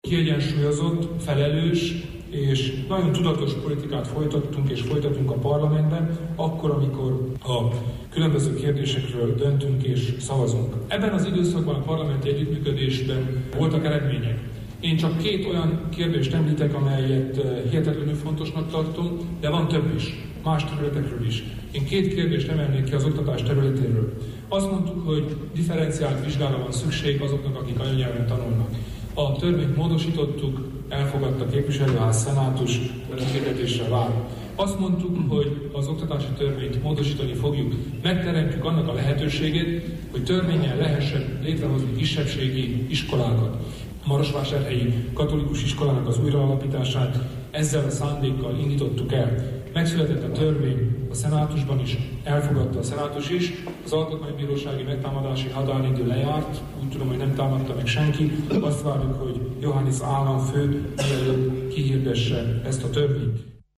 Részletet hallhatnak Kelemen Hunor politikai beszámolójából.